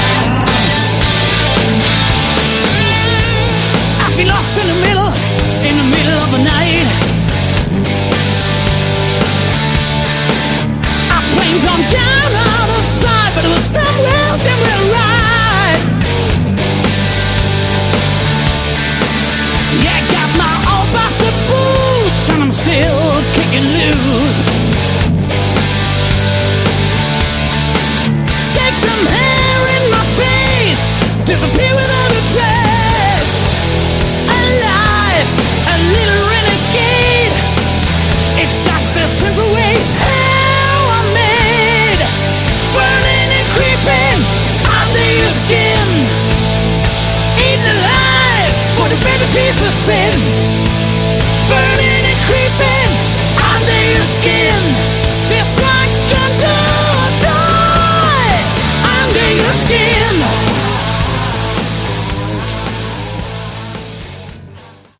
Category: Hard Rock
Straight ahead hard rock, a few cool riffs and hooks.